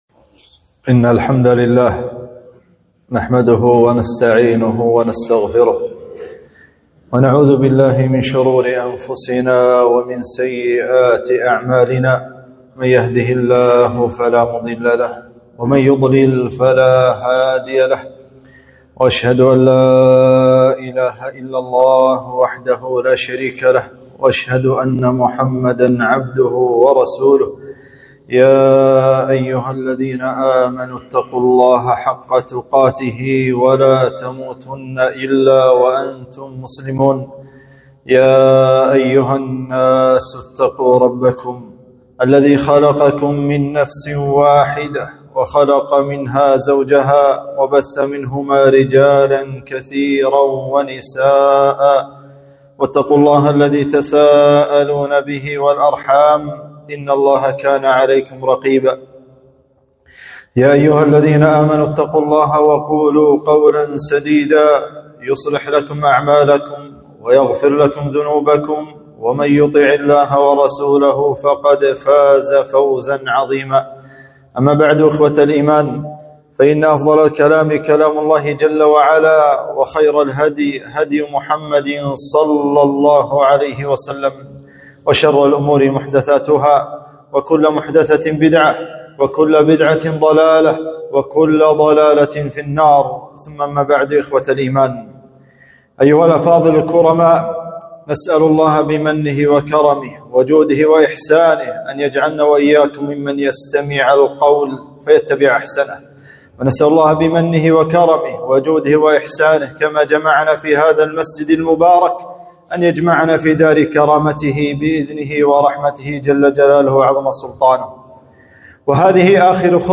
خطبة - قدوة الشباب ابن عباس